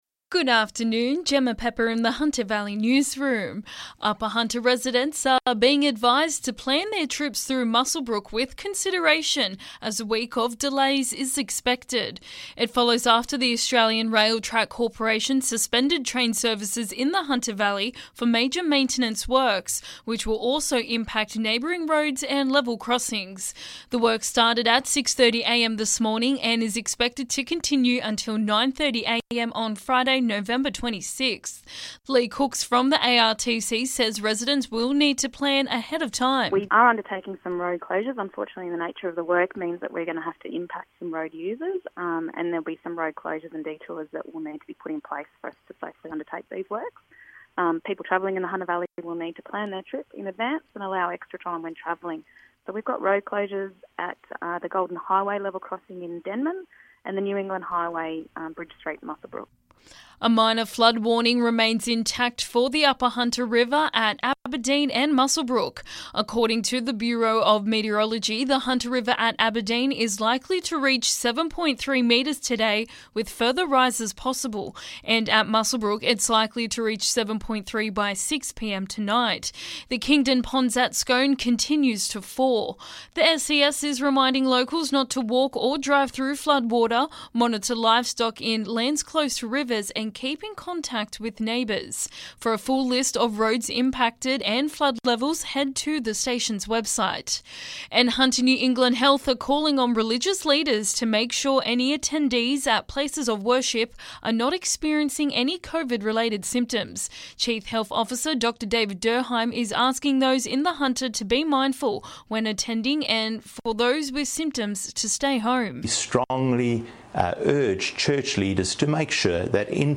LISTEN: Hunter Valley Local News Headlines